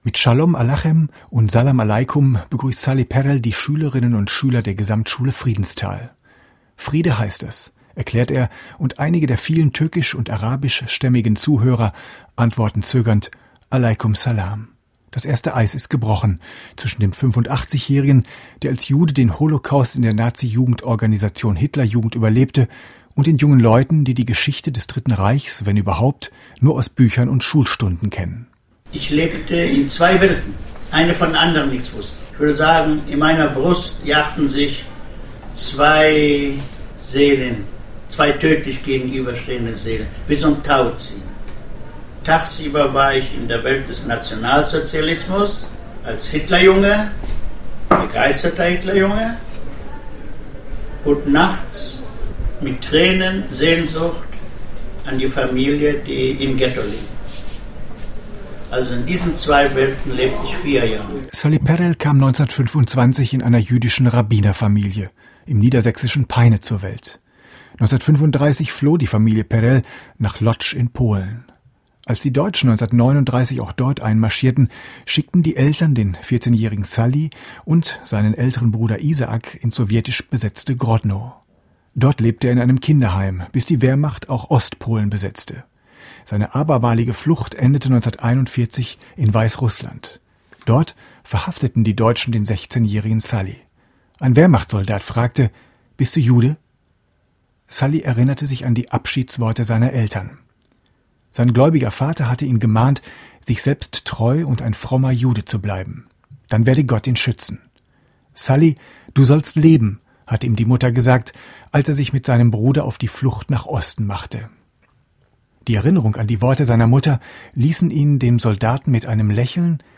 Radiobeiträge
Mit Schalom Alechem und Salaam Alejkum begrüßt Sally Perel die Schülerinnen und Schüler im Lernstudio der Gesamtschule Friedenstal.
Sally Perel alias "Hitlerjunge Salomon" erzählt Schüler/innen seine Lebensgeschichte